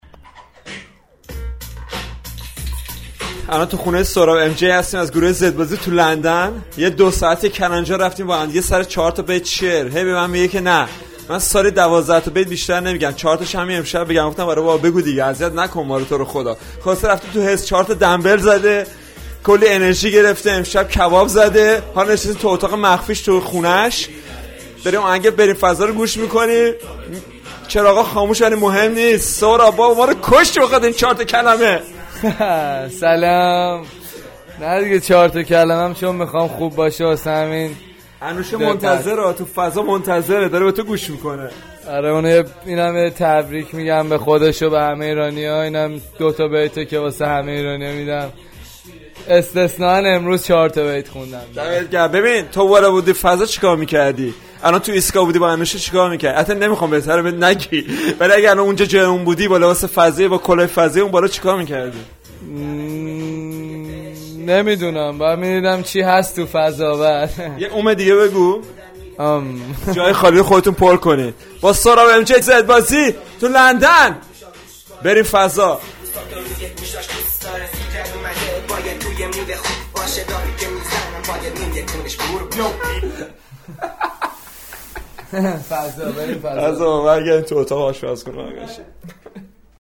تو اتاق بقل یه آهنگ رپ آمریکائی بلند داره پخش می شه.